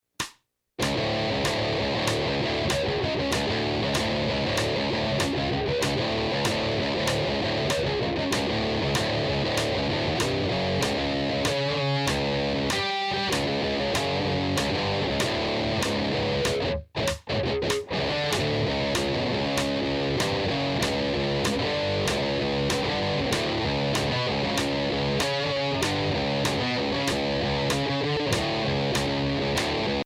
7-string Guitar
Voicing: Guitar Method